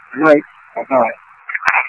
EVP's From Some Very Friendly Spirits
You'll hear me say "Goodnight" followed by two different EVP voices saying "Bye Bye" - "Goodnight"